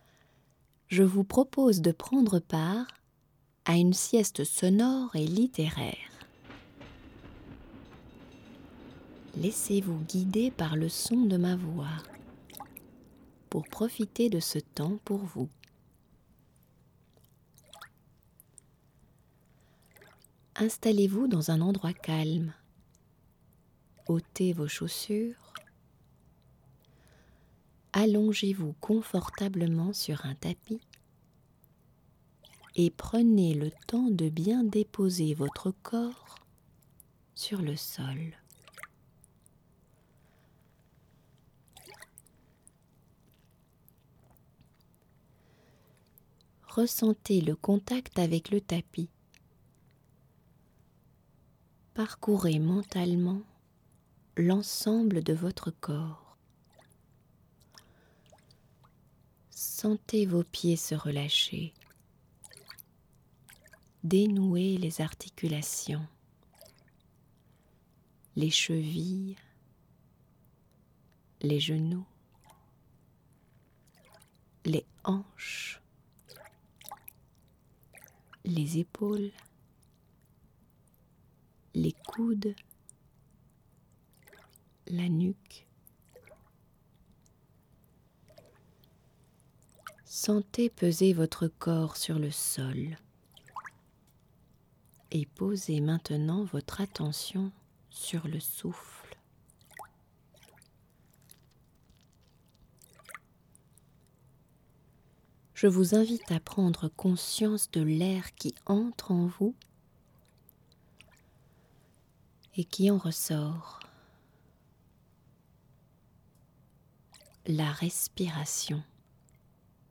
Sieste sonore et littéraire, spéciale Sainte-Barbe - Louvre-Lens